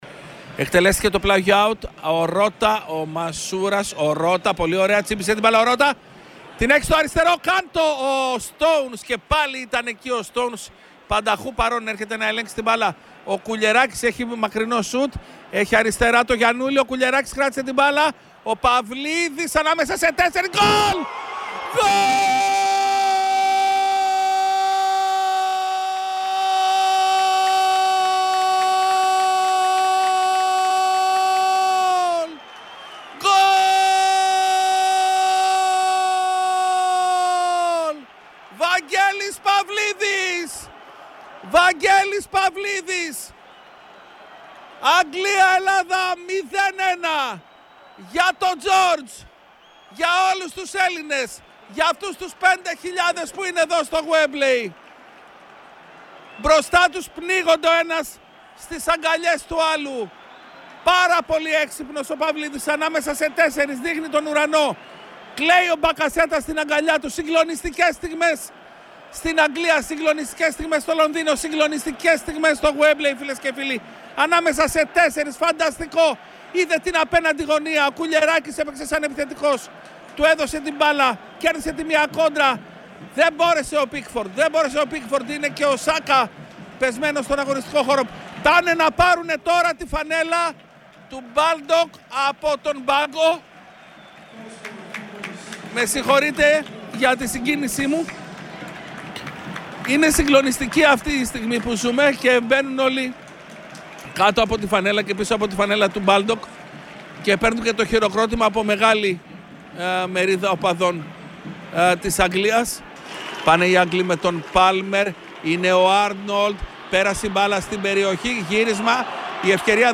Ο ιστορικός θρίαμβος της Ελλάδας κόντρα στην Αγγλία όπως τον μετέδωσε ο Real Fm 97.8